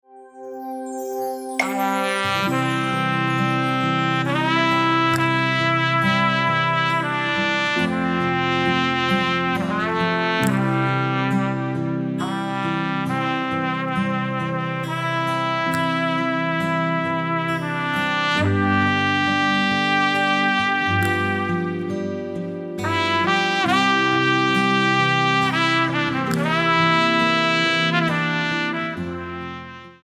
Instrumental-CD